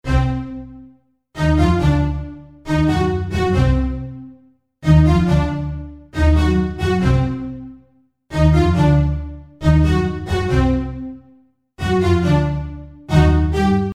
Tag: 138 bpm Dubstep Loops Synth Loops 2.34 MB wav Key : Unknown